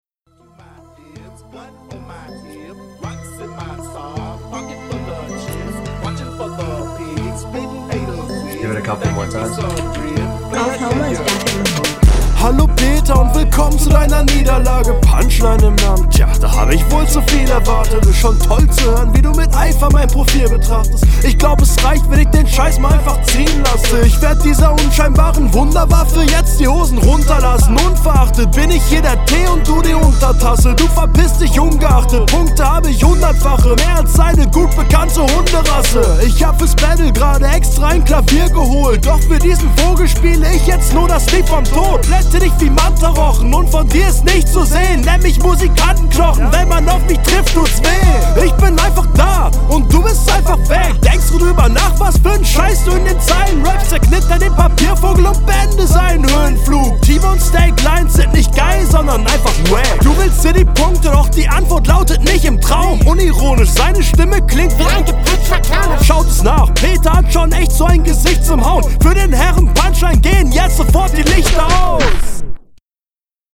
Find die Mische nicht so geil.